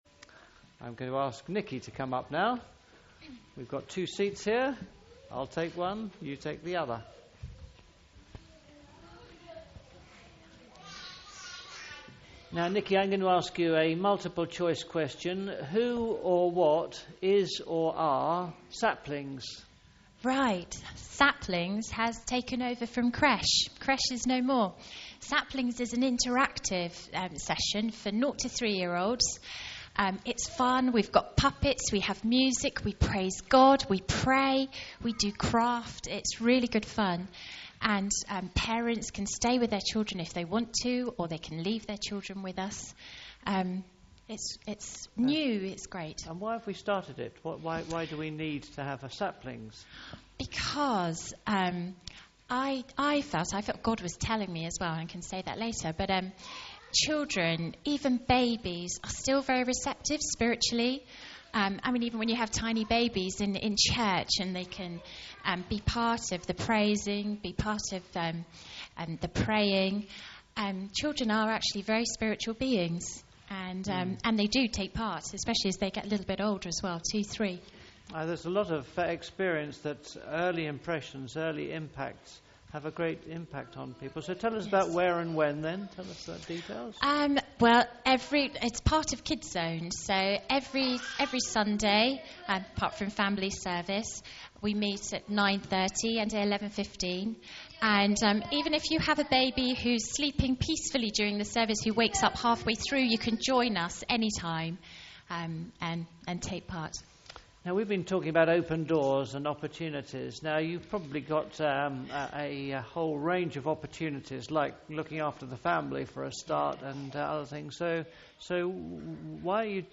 Saplings The Interview